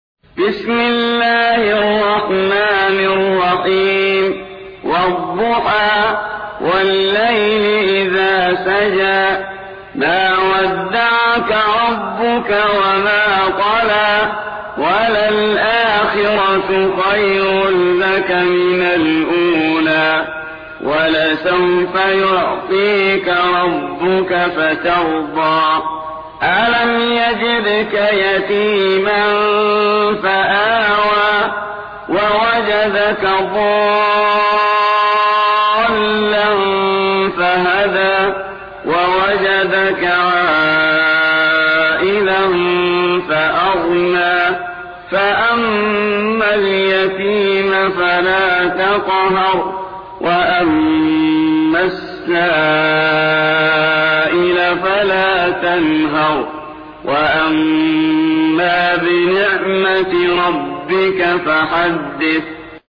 93. سورة الضحى / القارئ